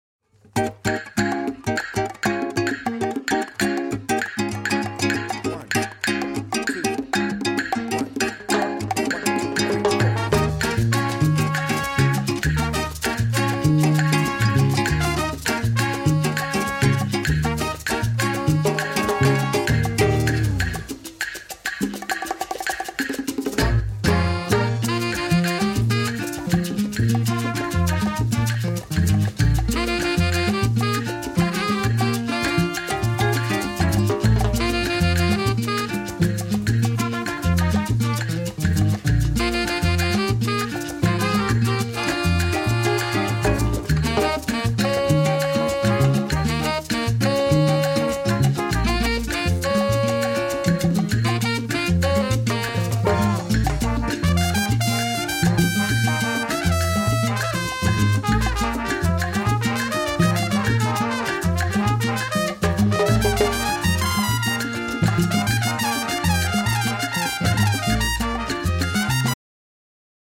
6 piece band
Great for dancing cumbia, meringue, twist.